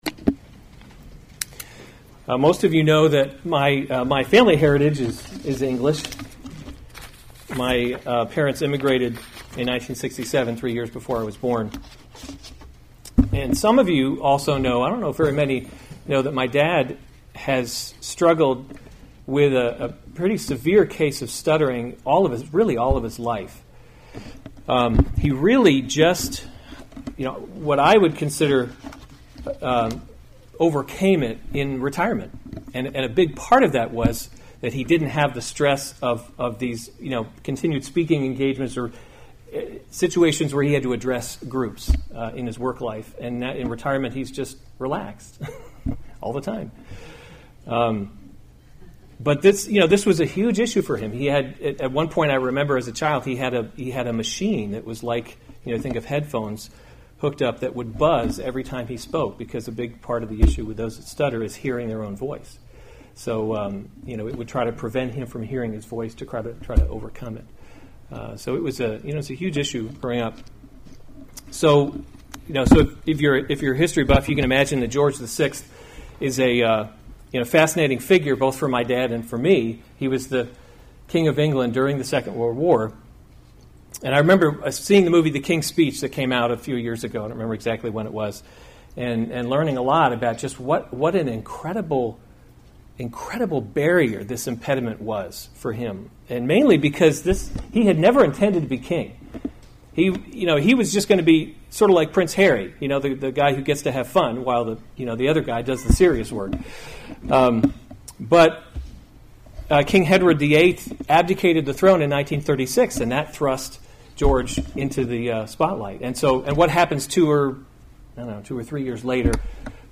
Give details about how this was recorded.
August 11, 2018 Psalms – Summer Series series Weekly Sunday Service Save/Download this sermon Psalm 90 Other sermons from Psalm Book Four From Everlasting to Everlasting A Prayer of Moses, […]